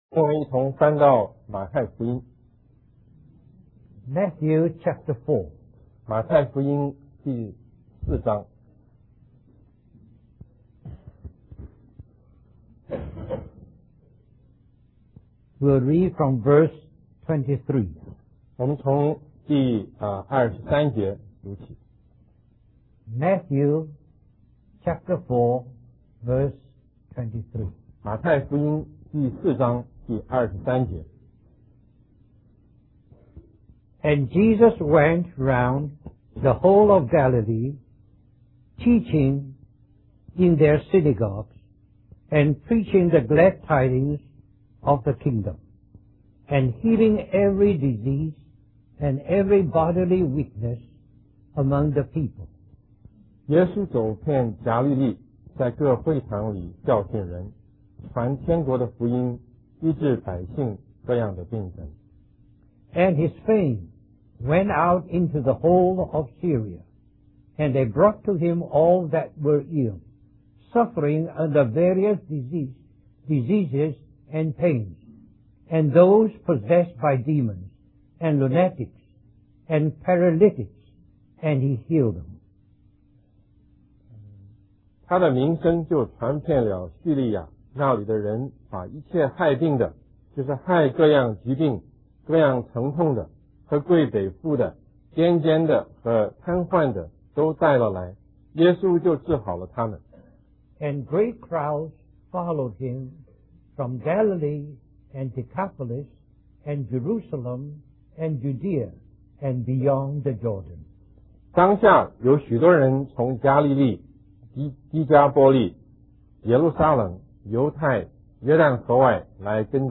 Alhambra, CA, US